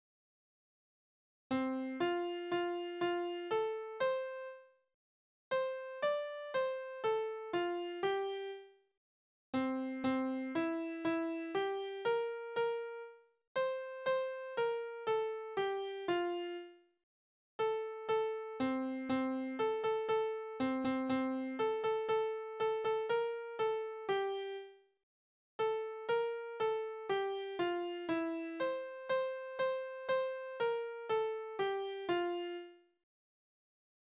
Soldatenlieder: Läufeknicken
Tonart: F-Dur
Taktart: C (4/4)
Tonumfang: große None
Besetzung: vokal